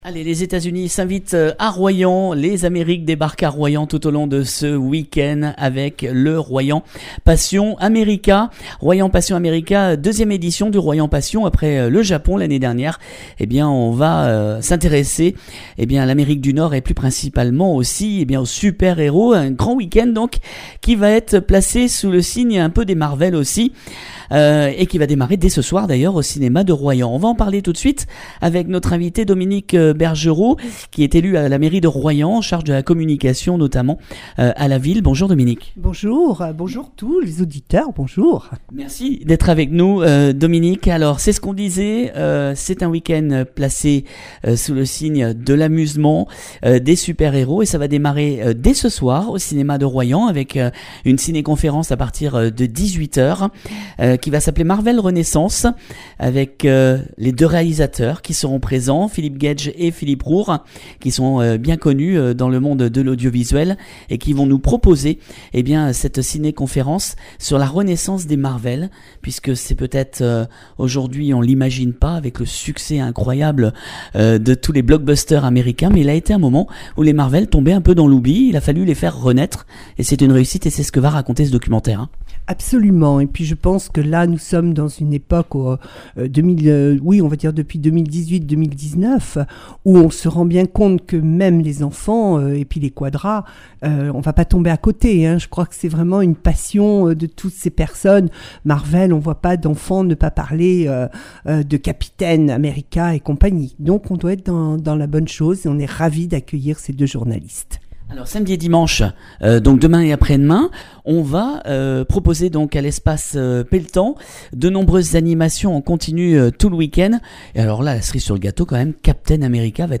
voici la présentation de l’édition par Dominique Bergerot élue à la communication de Royan